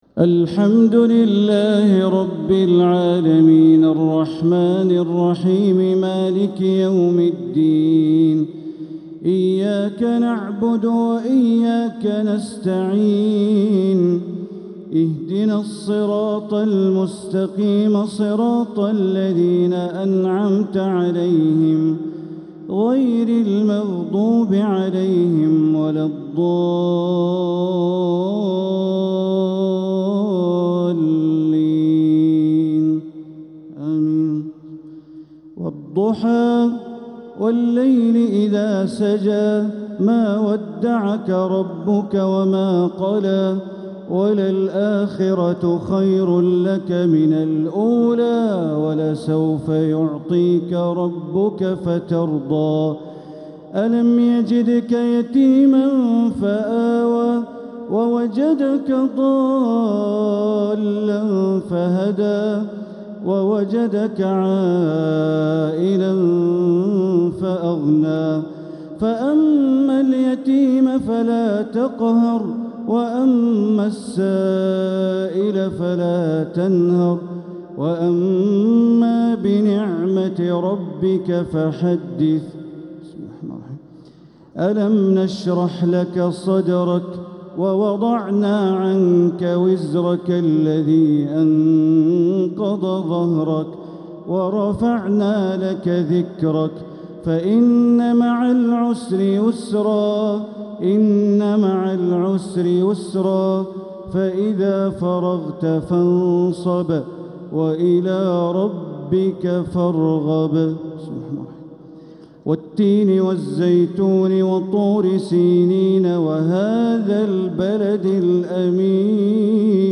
| taraweeh 29th night Ramadan 1446H Surah Ad-Duha to Surah AlKauthar > Taraweeh Ramadan 1446H > Taraweeh - Bandar Baleela Recitations